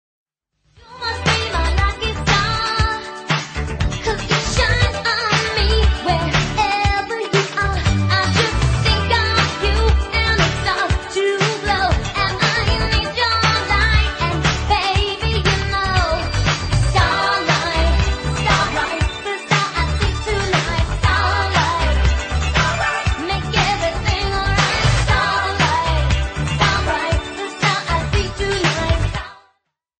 pop music
Ringtone